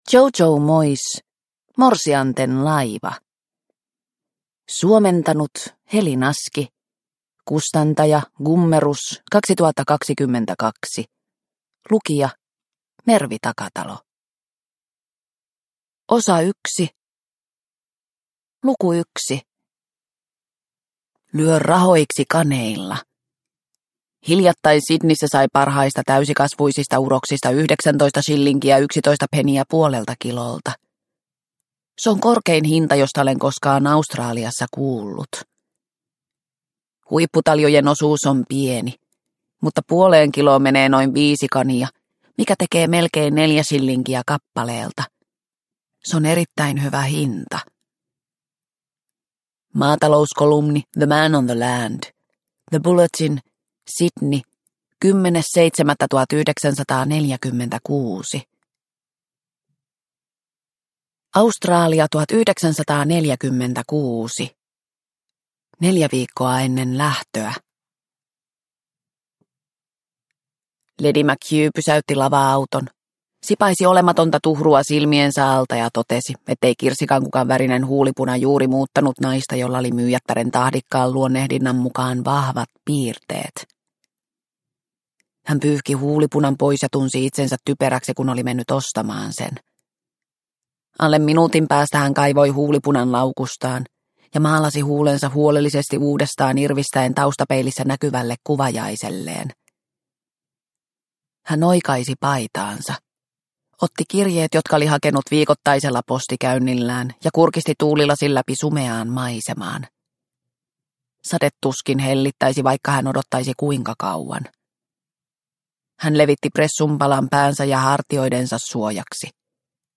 Morsianten laiva – Ljudbok – Laddas ner